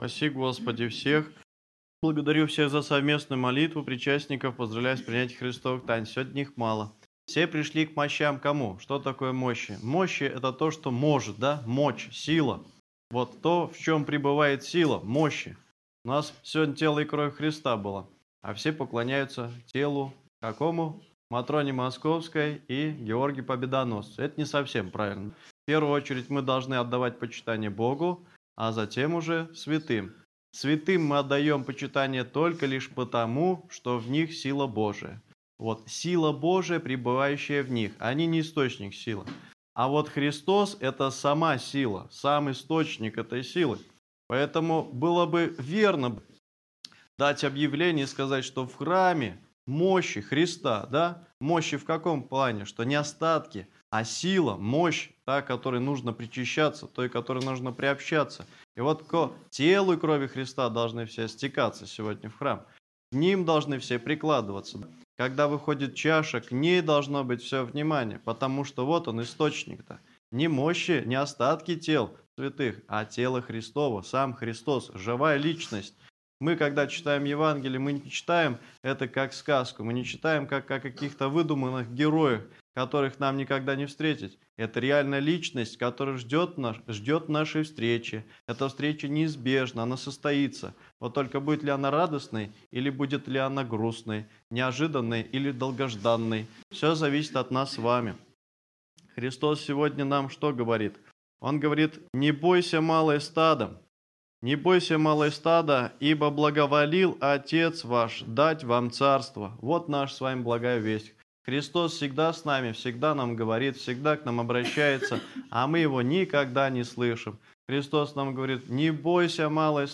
Аудио: Проповедь на утренней службе 15 декабря
Проповедь-на-утренней-службе-15-декабря.mp3